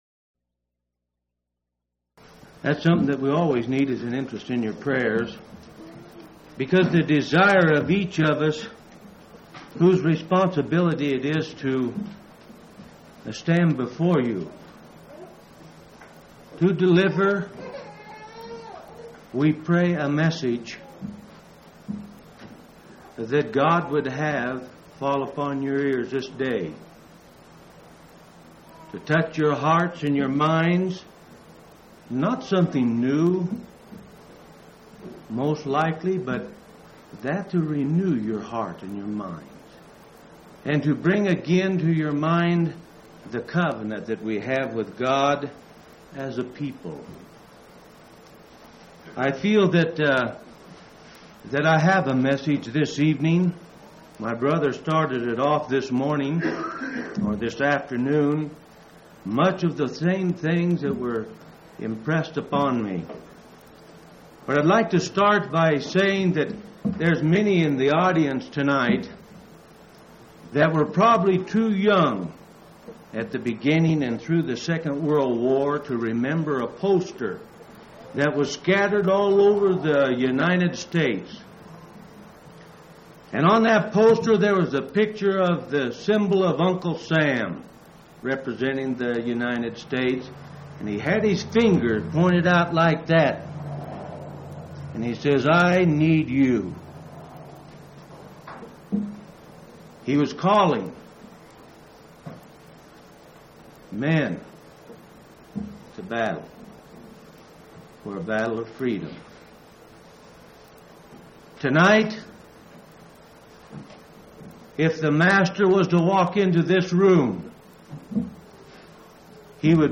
6/12/1981 Location: Colorado Reunion Event